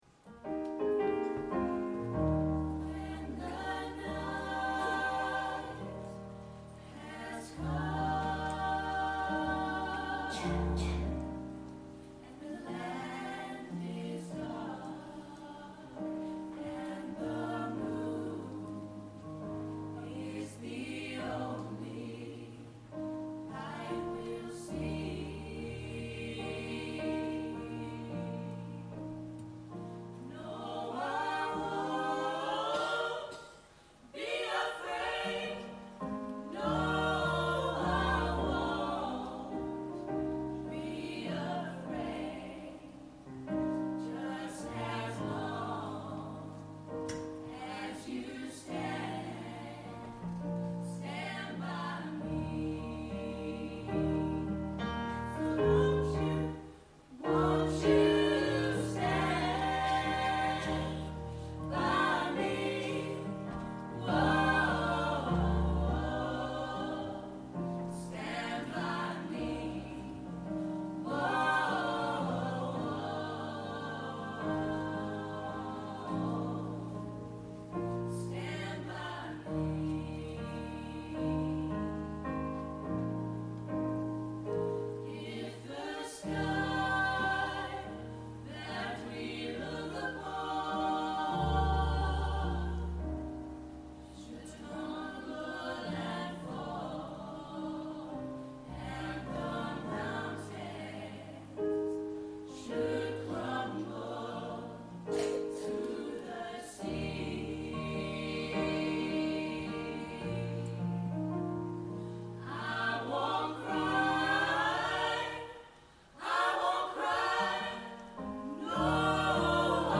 Performed at the Autumn Concert, November 2014 at the Broxbourne Civic Hall.